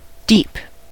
deep: Wikimedia Commons US English Pronunciations
En-us-deep.WAV